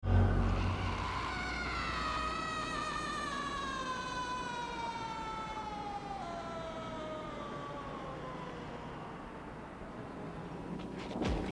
scream.mp3